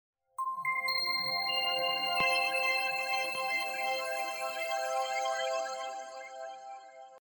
Перебивка канала